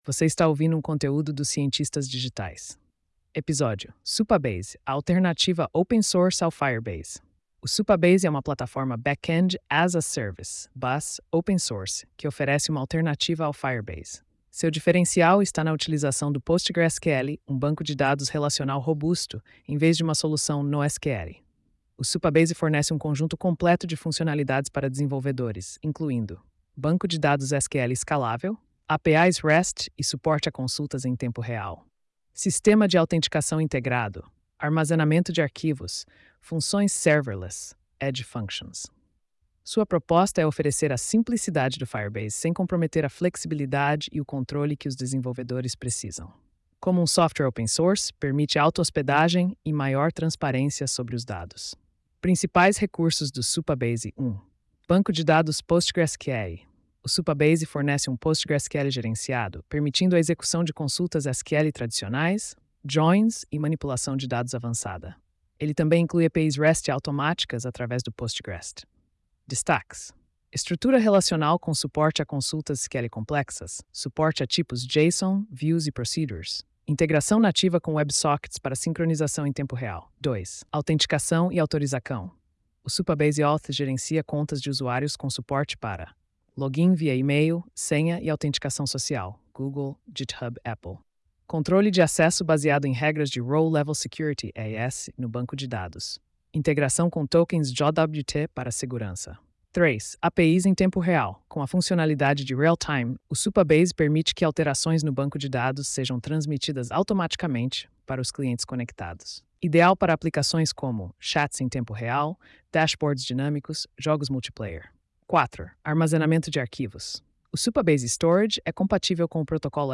post-2793-tts.mp3